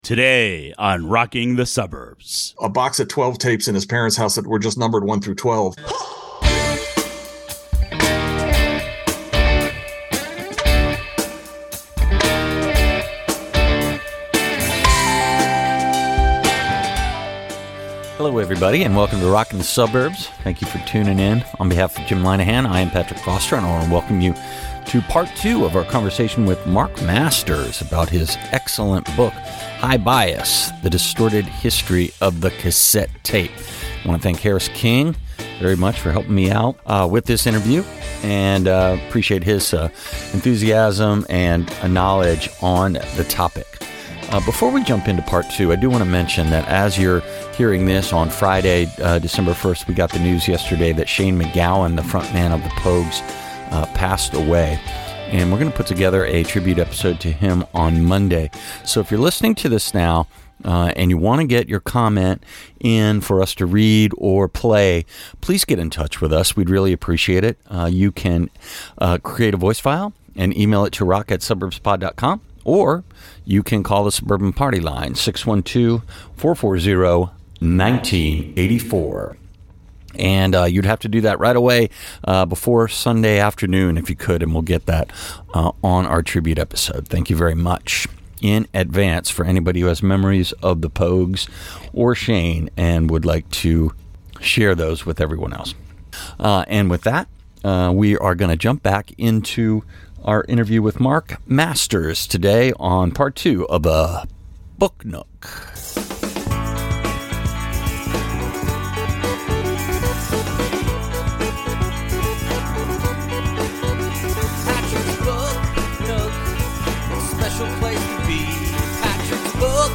Book Nook Interview Part 2